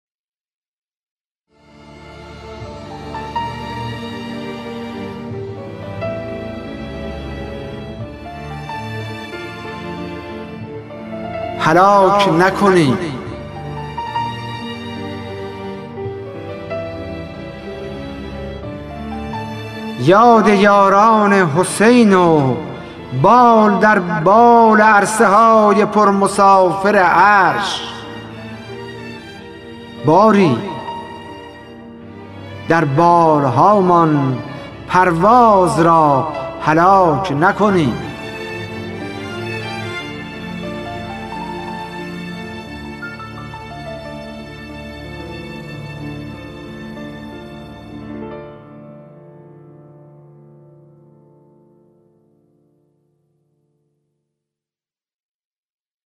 خوانش شعر سپید عاشورایی / ۶